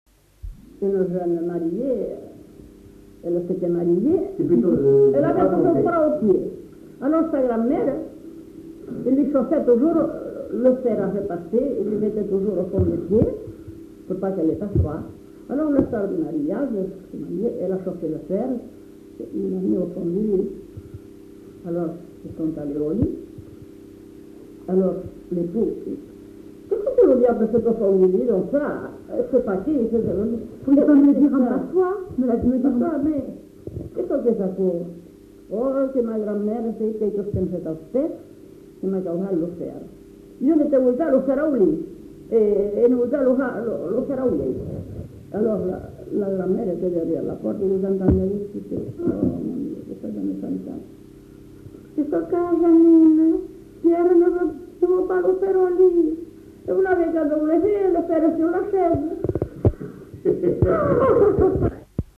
Lieu : [sans lieu] ; Landes
Genre : conte-légende-récit
Effectif : 1
Type de voix : voix de femme
Production du son : parlé